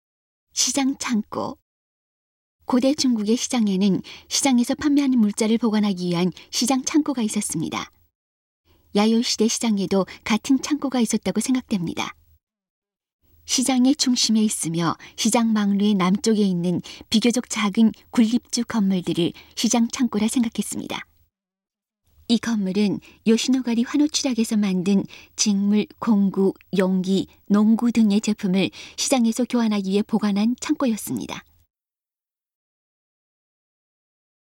이 건물은 요시노가리 환호취락에서 만든 직물, 공구, 용기, 농기구 등의 제품을 시장에서 교환하기 위해 보관한 창고라 추측하고 있습니다. 음성 가이드 이전 페이지 다음 페이지 휴대전화 가이드 처음으로 (C)YOSHINOGARI HISTORICAL PARK